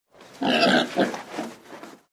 ACTIVITAT 8. QUIN ANIMAL FA AQUEST SOROLL?
porc.mp3